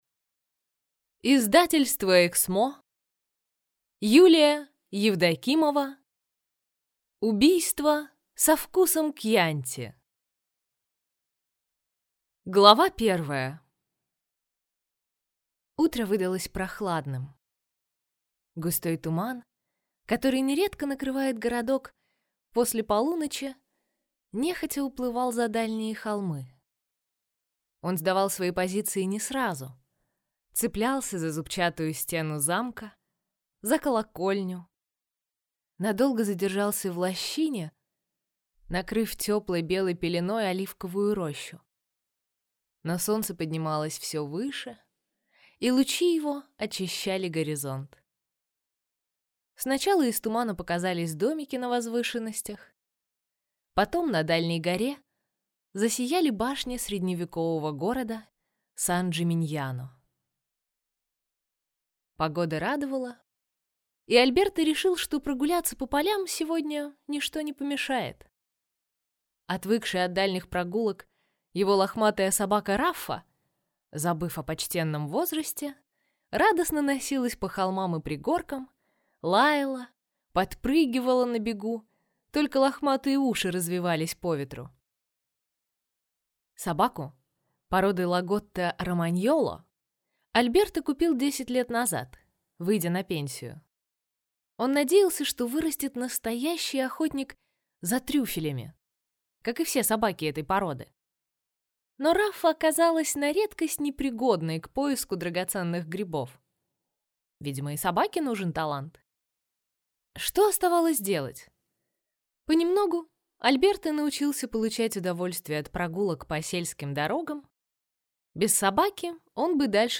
Аудиокнига Убийство со вкусом кьянти | Библиотека аудиокниг